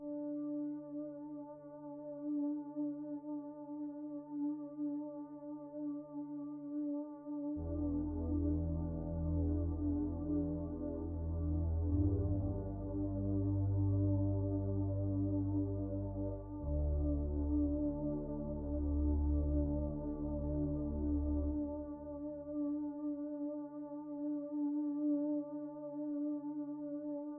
vgm,